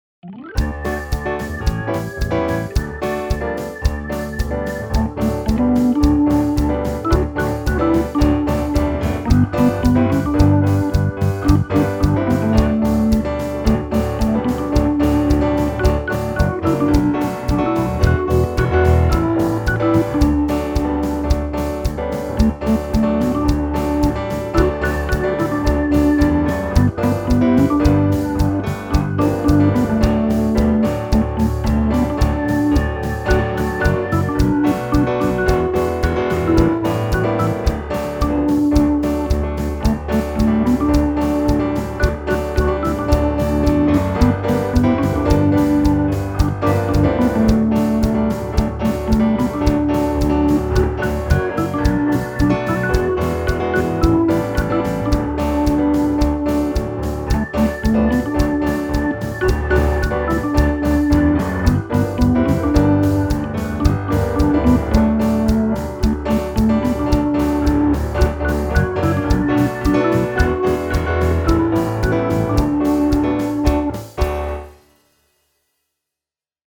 This Little Light of Mine Instrumental Track